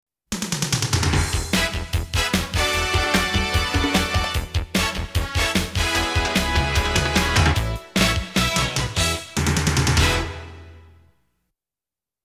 激情的背景音乐